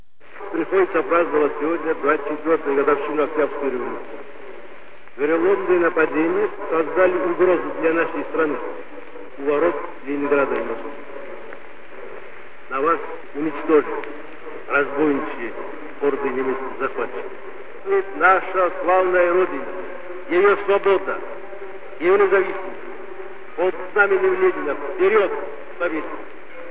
une dernière fois, lors de l'un de ces discours.